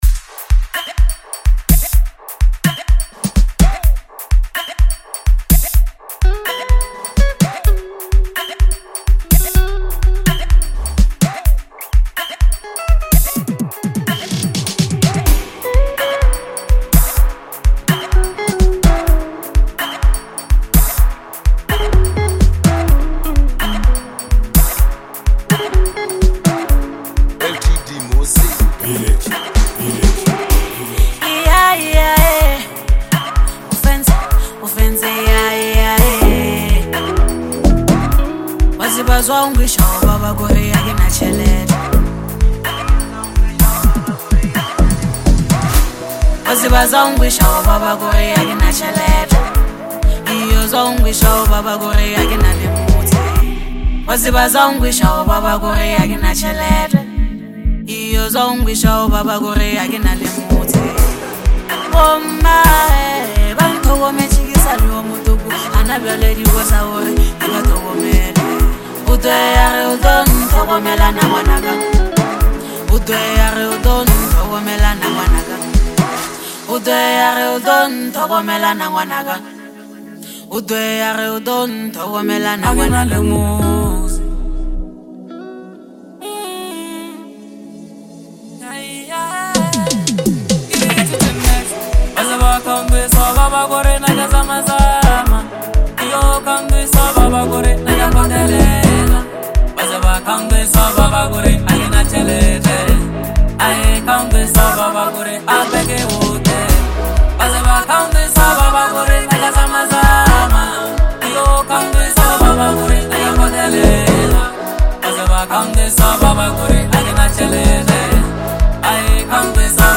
emotionally charged and musically rich anthem